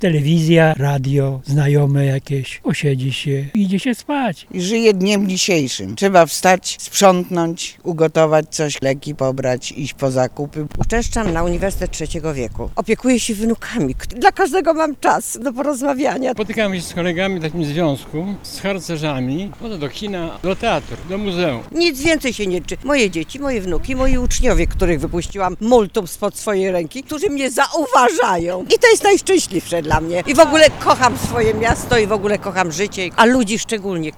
Nasza reporterka